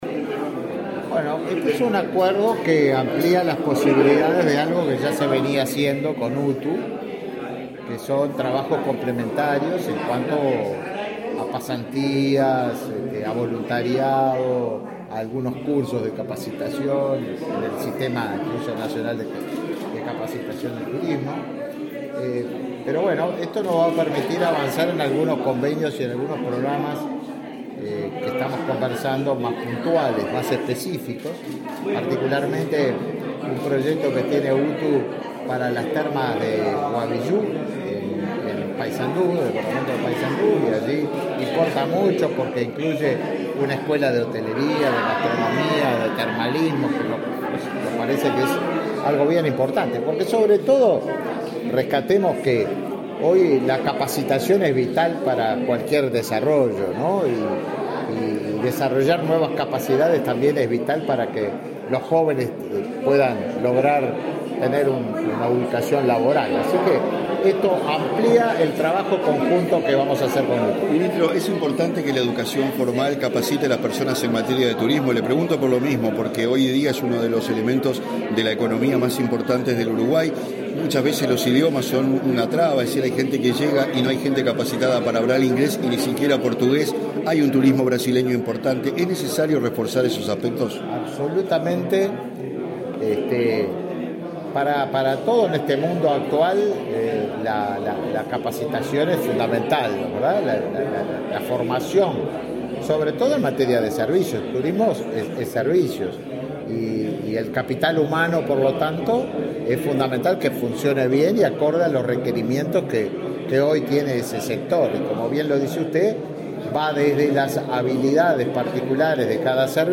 Declaraciones a la prensa del ministro de Turismo, Tabaré Viera
Luego, el ministro Tabaré Viera dialogó con la prensa.